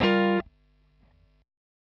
Gm7.wav